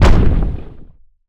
rock_impact_heavy_slam_01.wav